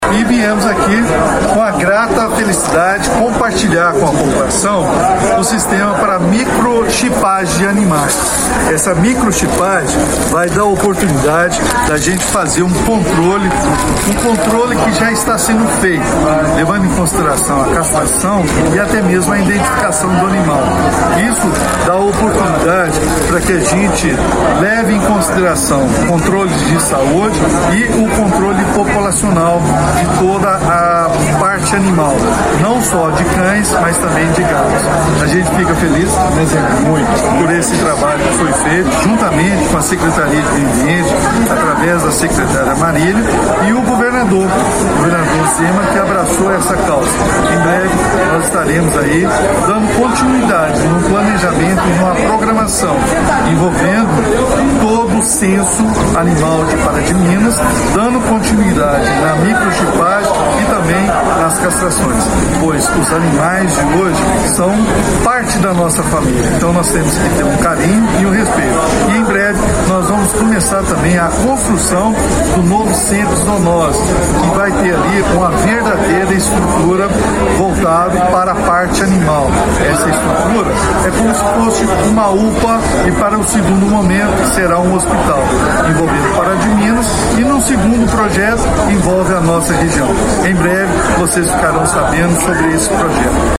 O prefeito Elias Diniz também adiantou que em breve o Centro de Controle de Zoonoses (CCZ) começará a ser reformado para se transformar em uma unidade de pronto atendimento de animais: